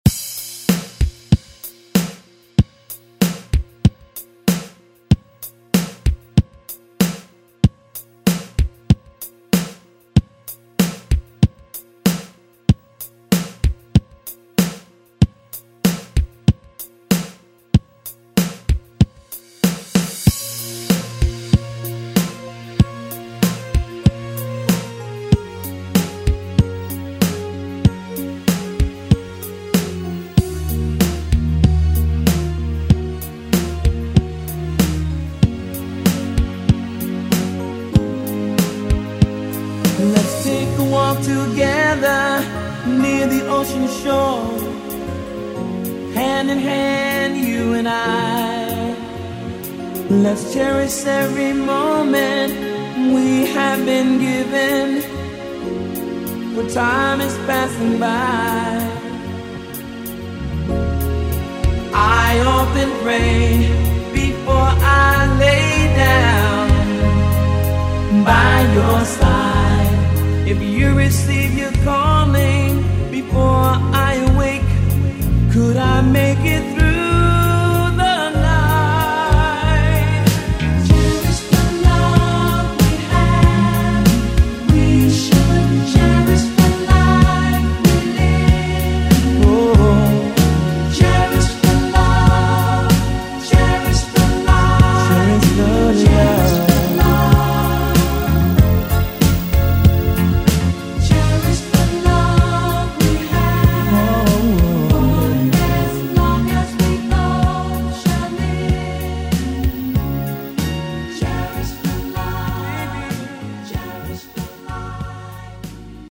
Electronic Pop Funk Soul Music
Genres: DANCE , RE-DRUM Version: Clean BPM: 108 Time